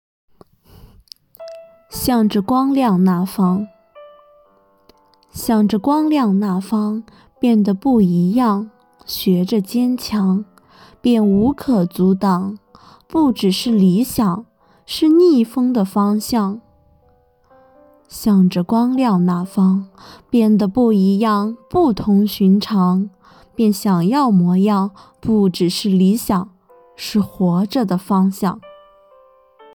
“阅读的力量 -- 读给你听”主题朗诵